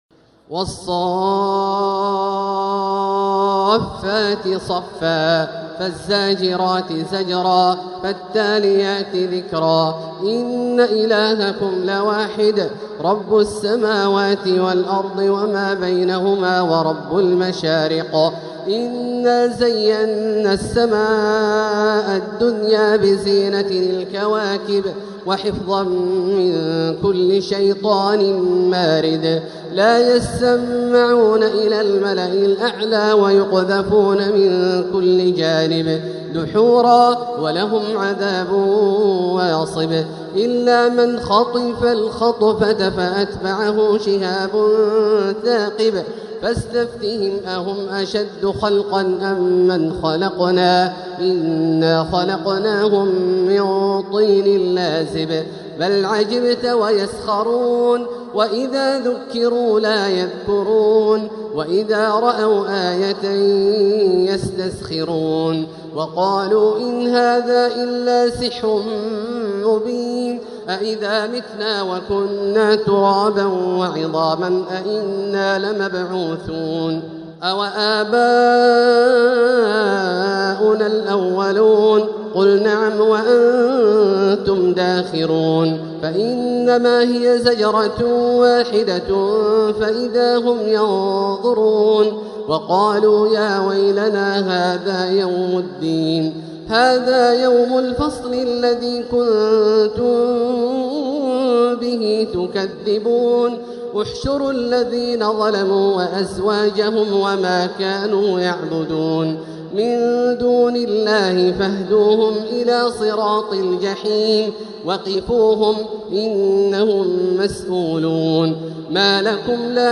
السور المكتملة لـ الشيخ أ.د. عبدالله الجهني من ليالي شهر رمضان 1446هـ | الحلقة الثامنة > سلسلة السور المكتملة من ليالي شهر رمضان المبارك | الشيخ د. عبدالله الجهني > المزيد - تلاوات عبدالله الجهني